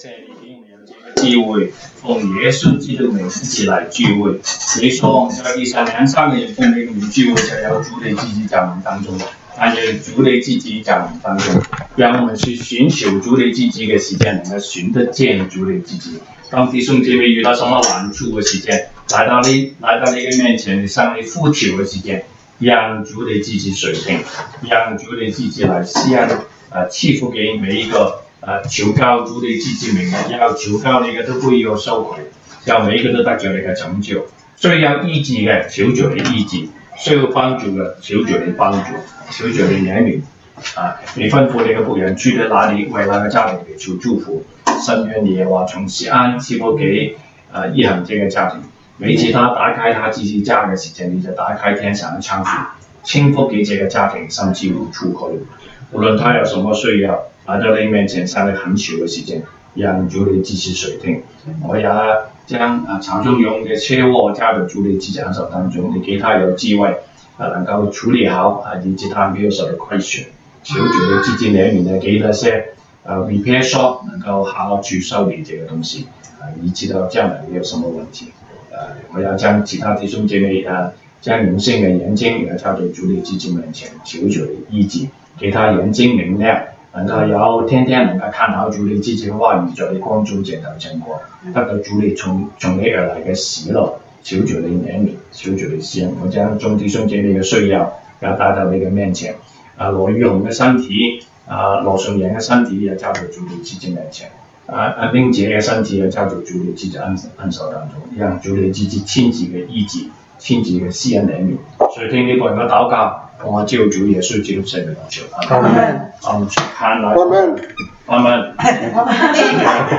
Service Type: 週一國語研經 Monday Bible Study